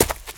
STEPS Leaves, Run 27.wav